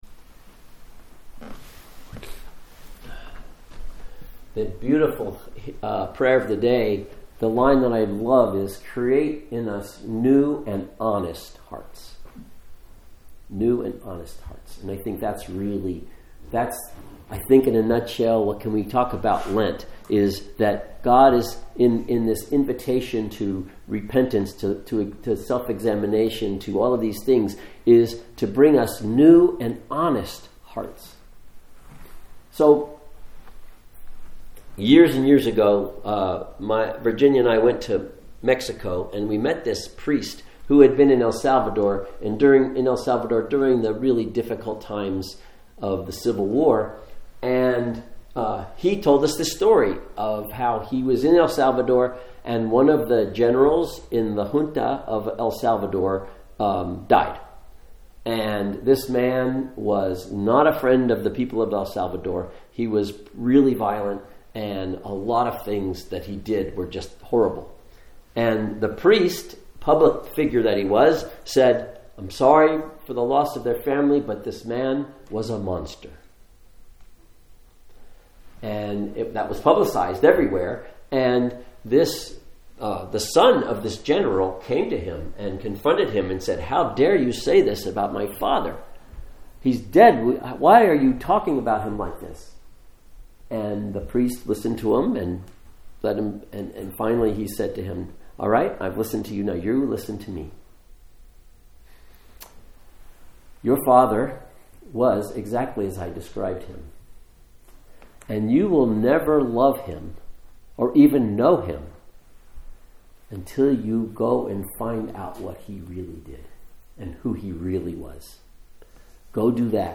Ash Wednesday Service